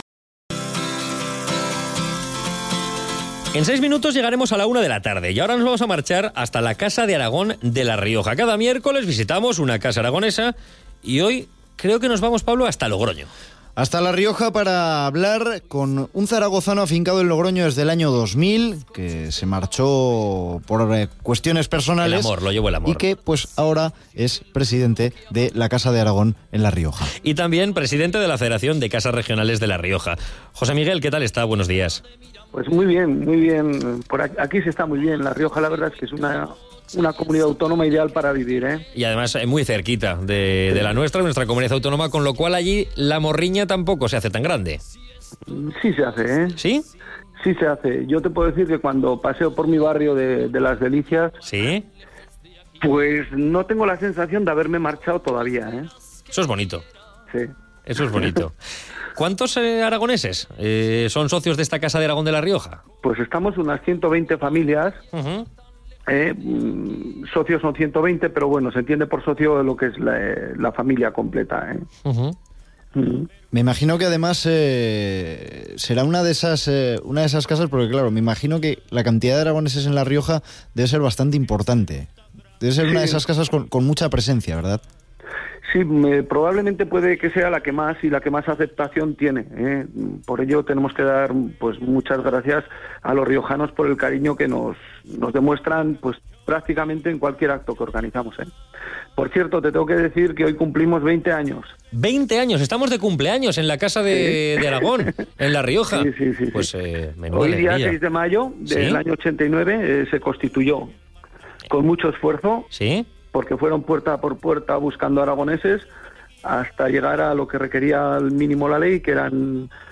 - Entrevista en Aragón Radio 2.